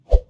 Close Combat Swing Sound 73.wav